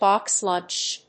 bóx lúnch
音節bóx lùnch